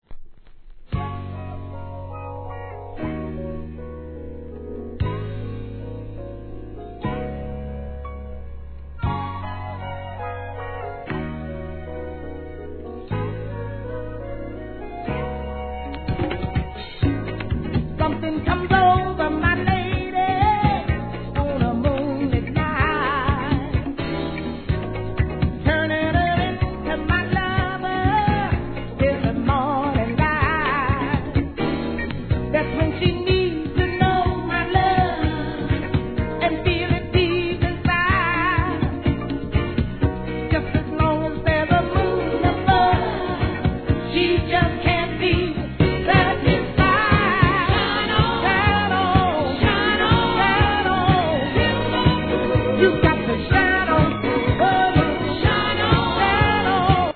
¥ 550 税込 関連カテゴリ SOUL/FUNK/etc...
2人のVO.も爽やかな、ガラージ〜ディスコクラシック